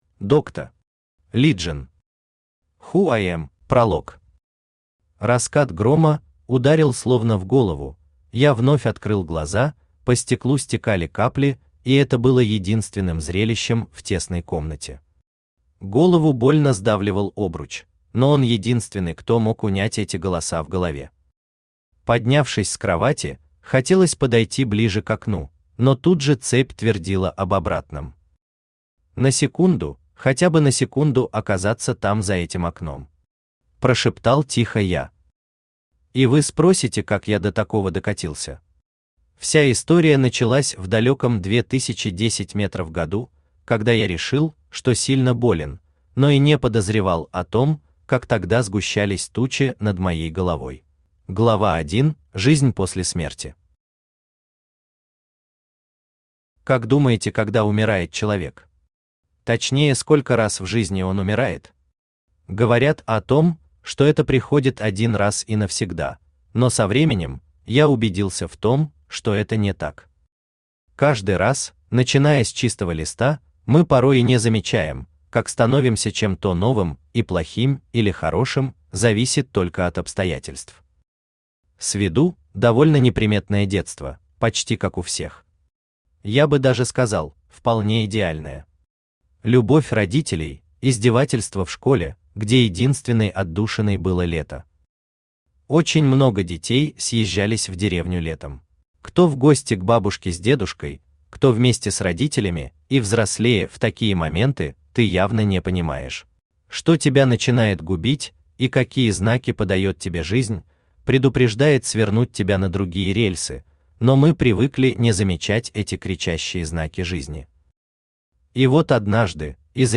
Аудиокнига Who I"m?
Автор Dr.Legion Читает аудиокнигу Авточтец ЛитРес.